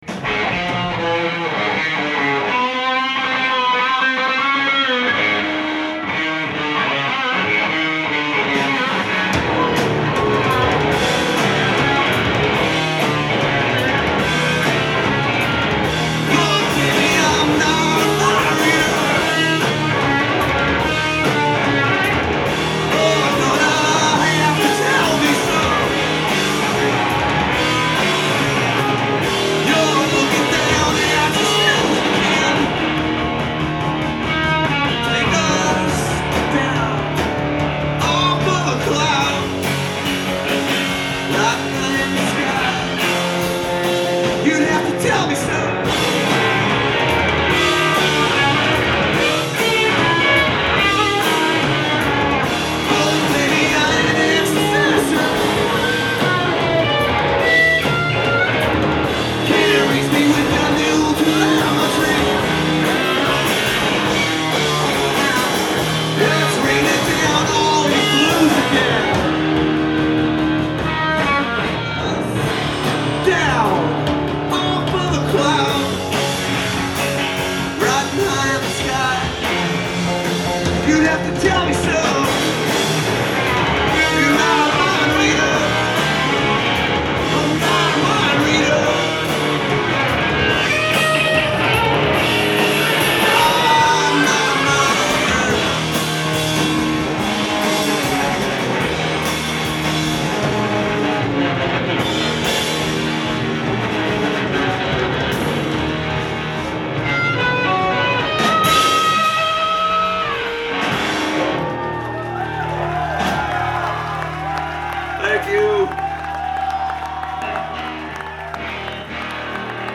Live at the Paradise
in Boston, Massachusetts